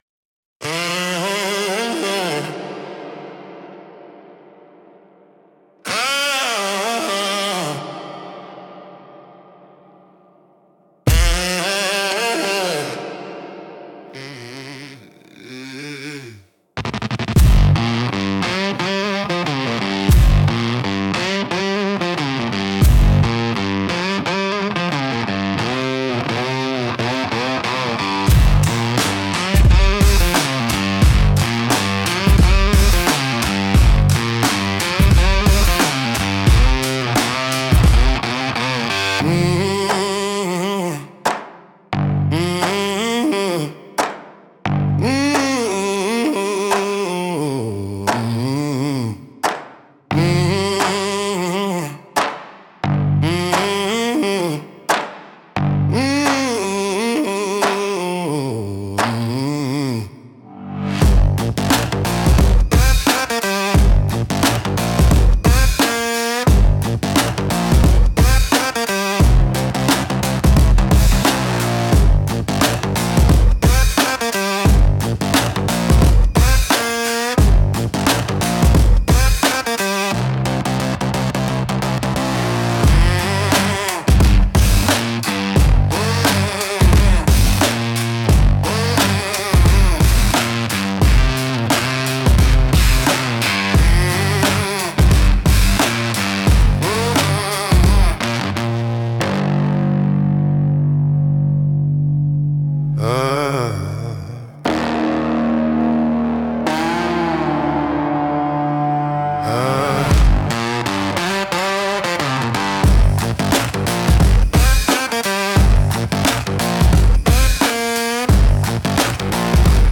Instrumental - Voltage in the Blood 2.39